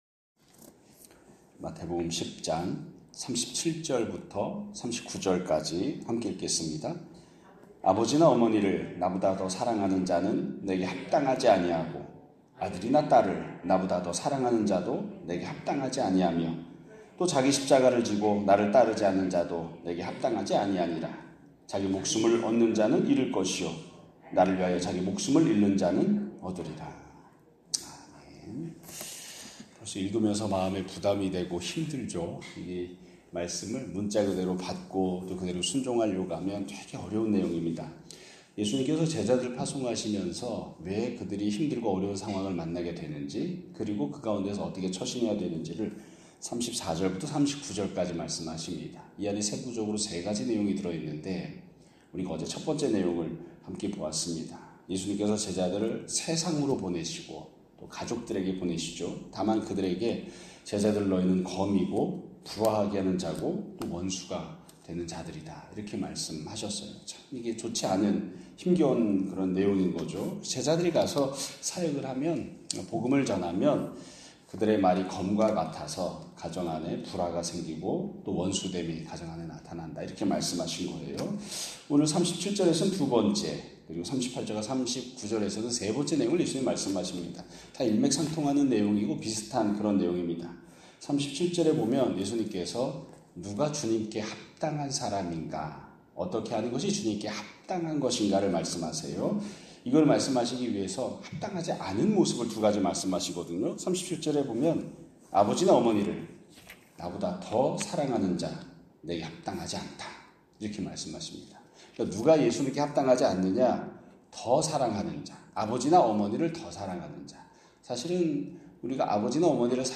2025년 8월 19일 (화요일) <아침예배> 설교입니다.